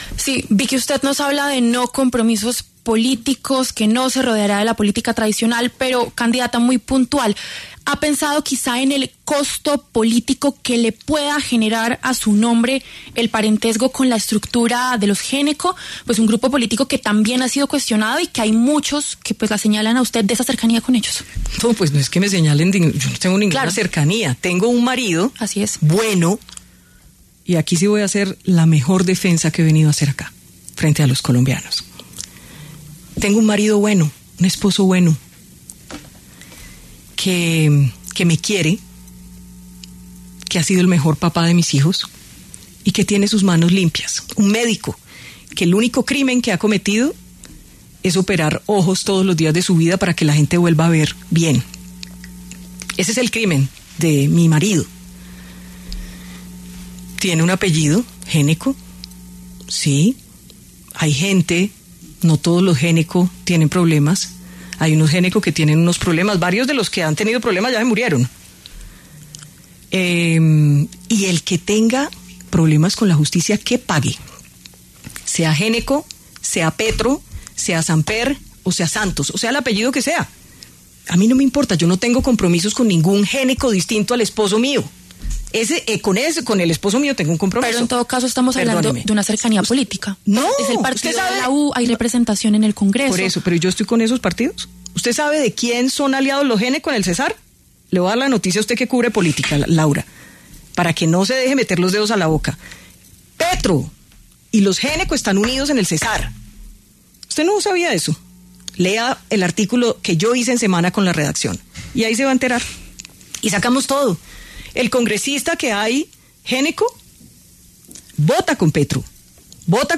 La periodista Victoria Eugenia Dávila Hoyos, más conocida como Vicky Dávila, pasó por los micrófonos de La W, con Julio Sánchez Cristo, para hablar sobre su precandidatura presidencial para 2026. Durante la entrevista tocó temas importantes como su relación con la política, cómo manejará el tema de la corrupción, las amenazas que ha recibido en su contra, entre otras cosas.